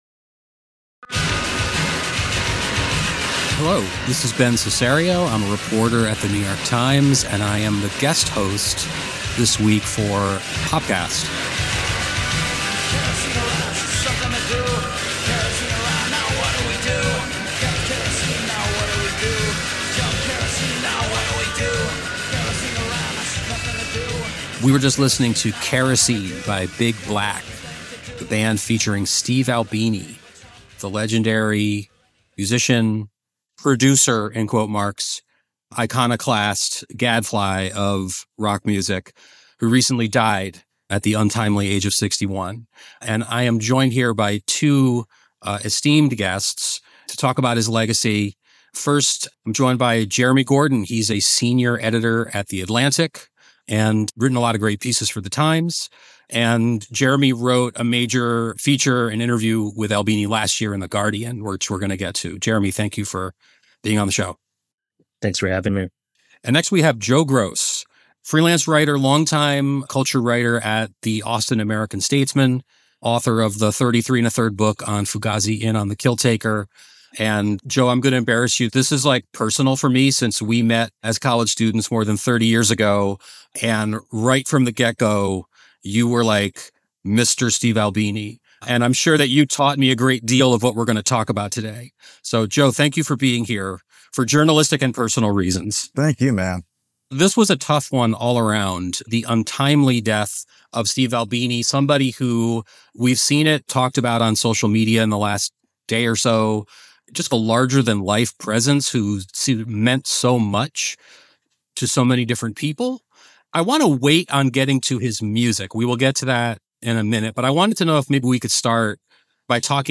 A conversation about one of the most admired, and divisive, figures in rock.